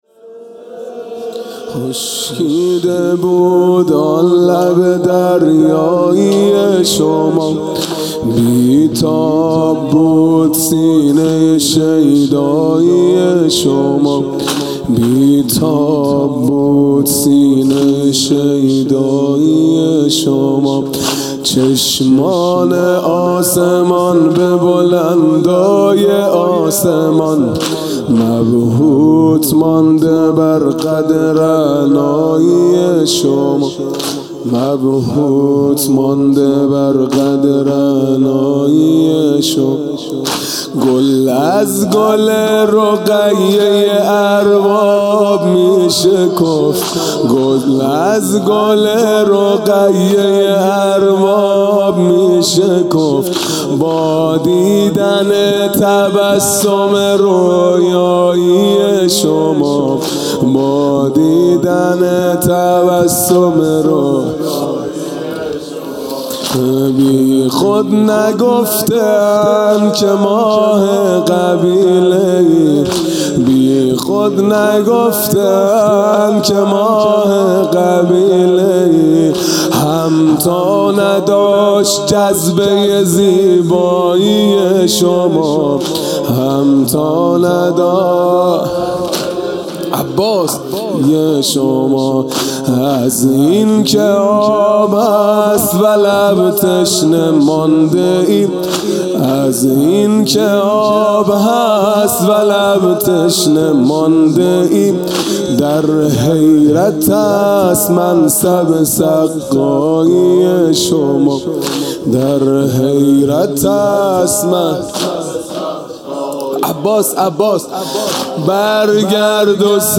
خیمه گاه - هیئت بچه های فاطمه (س) - واحد | خشکیده بود آن لب دریایی شما
محرم 1441 | صبح نهم